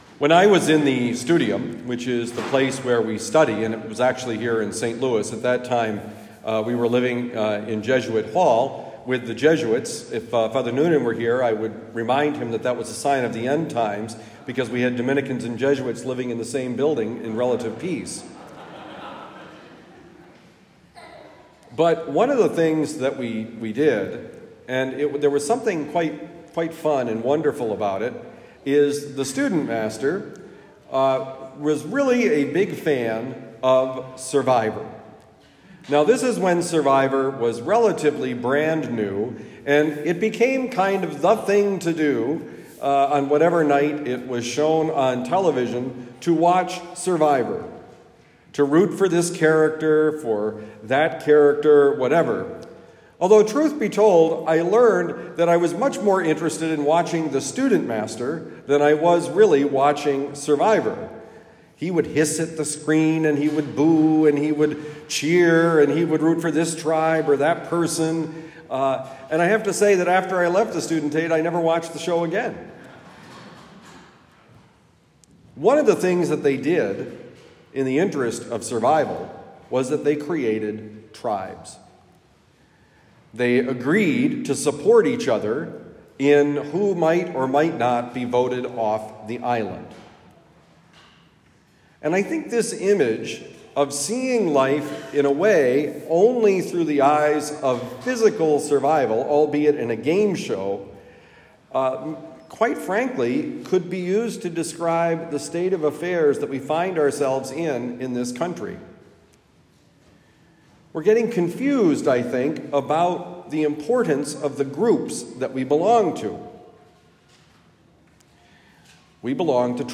Homily given at Our Lady of Lourdes Parish, University City, Missouri.